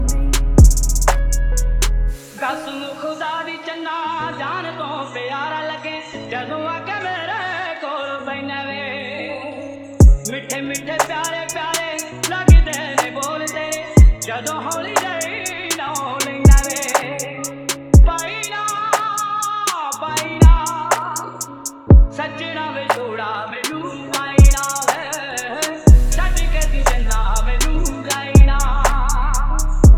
Жанр: Фолк-рок